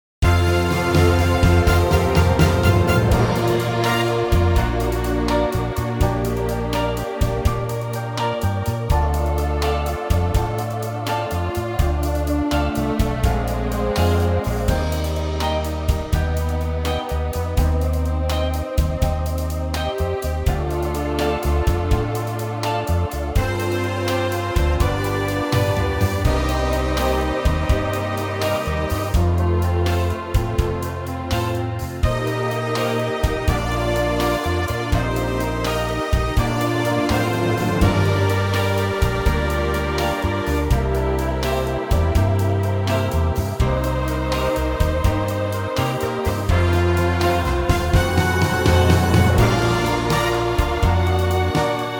key - Bb to B - vocal range - C to D# (optional F#)
Big arrangement
-Unique Backing Track Downloads